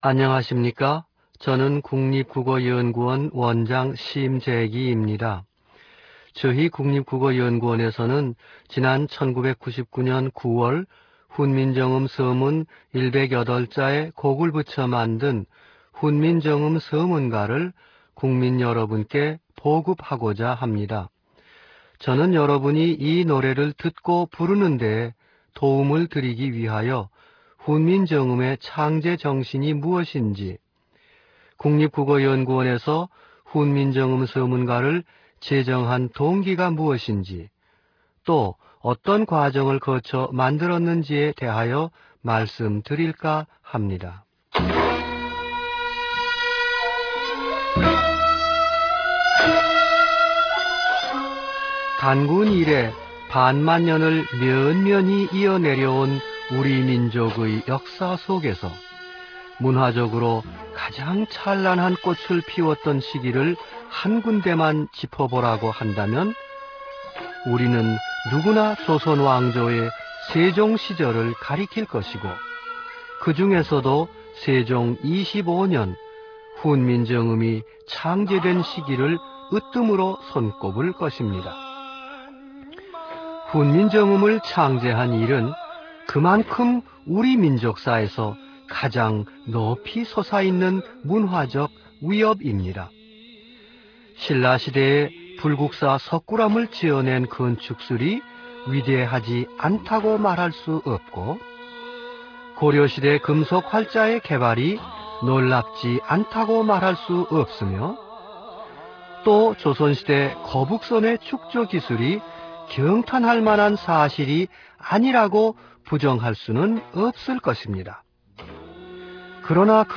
낭독: 심재기(국립국어연구원 원장) 최종 수정일: 이전글 훈민정음 서문가 해설 1 (21:05-mp3 파일) 다음글 훈민정음 서문가 반주(서양음악/1:55-mp3 파일)